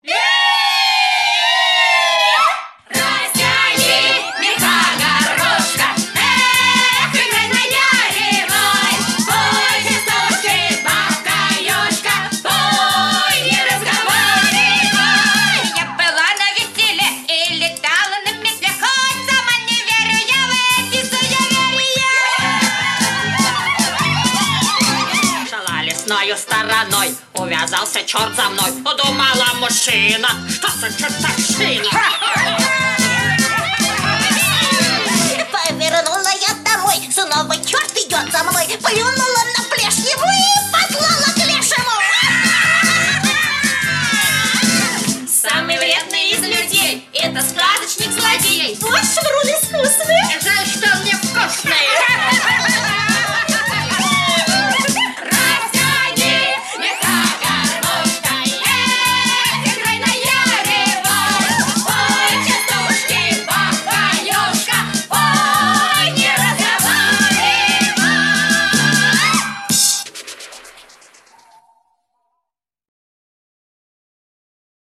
Очень веселые песенки-частушки